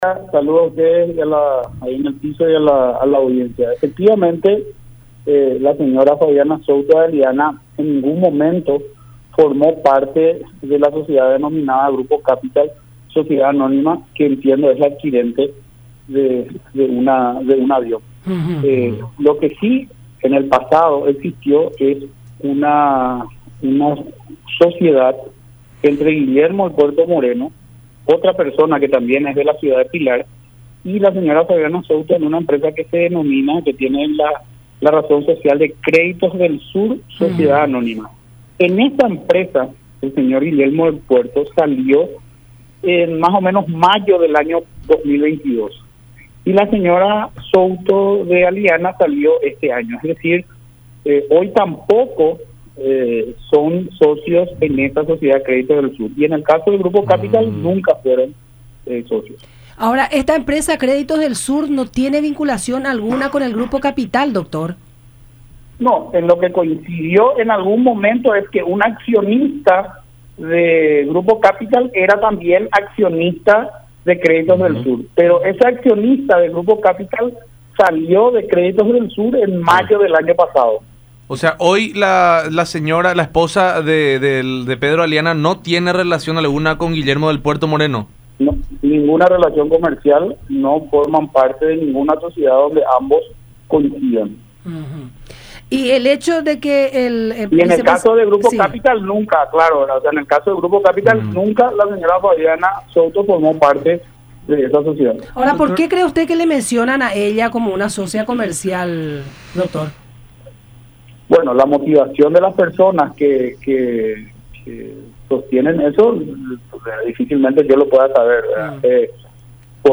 en conversación con La Unión Hace La Fuerza por Unión TV y radio La Unión